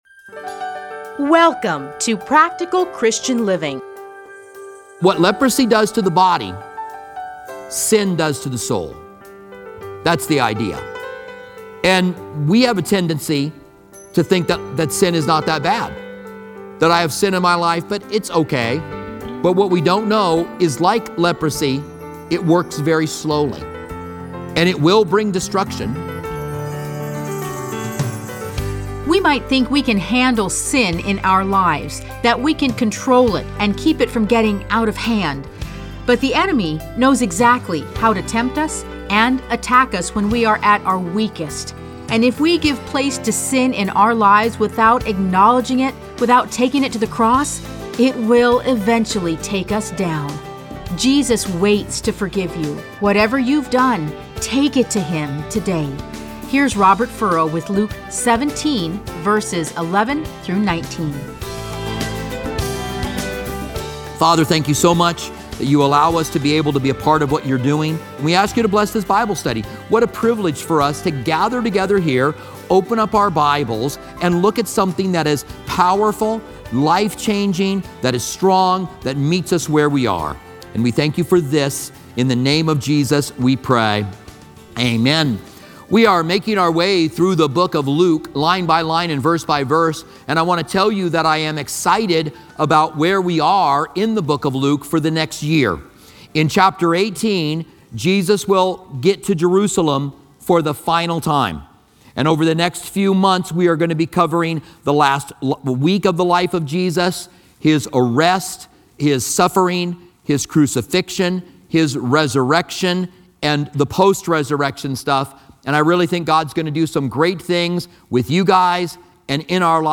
Listen to a teaching from Luke 17:11-19.